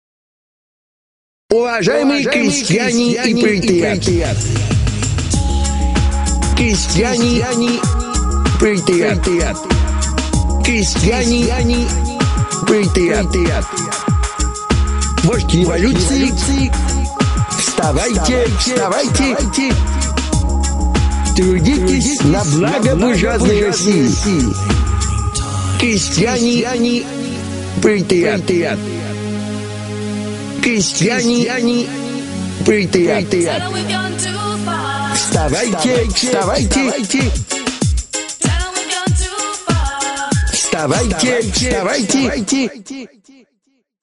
вождь революции призывает трудится на благо всех нас.